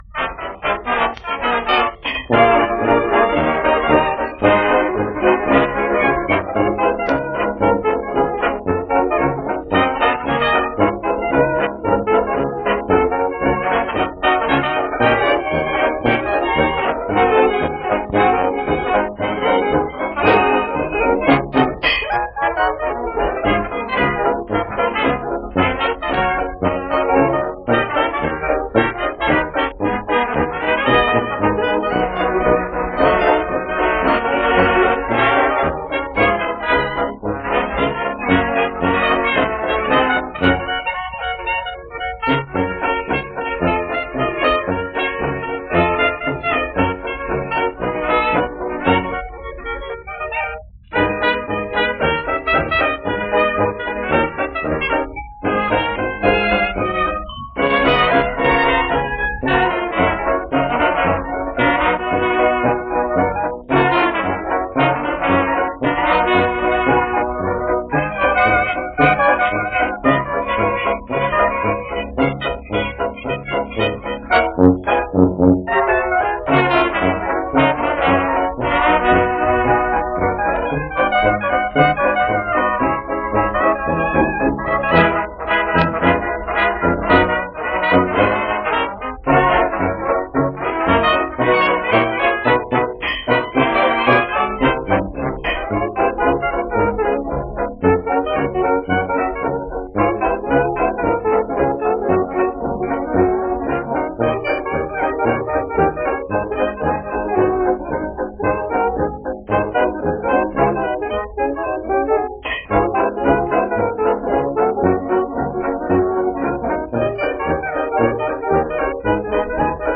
Two highly danceable tunes from 1925.